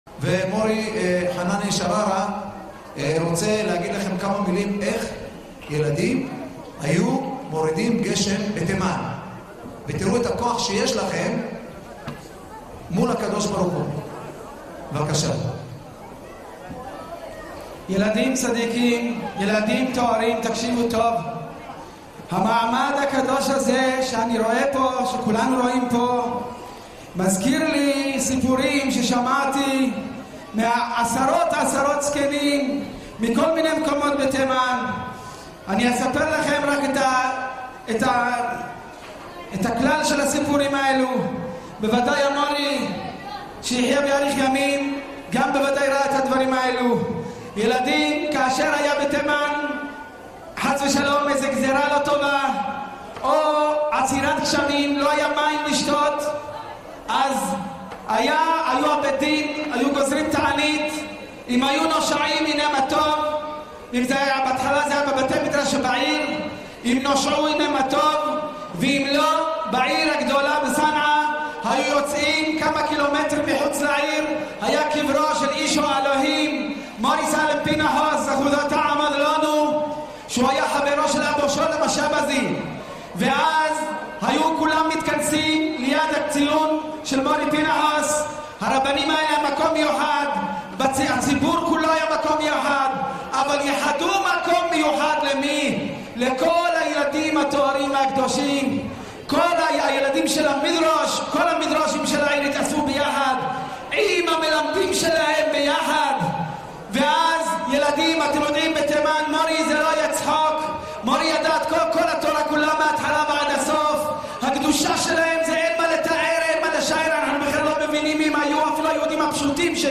בכנס האלף בהיכל ישיבת חברון - ארגון אלפי יהודה
דברי חיזוק והתעוררות ברגש רב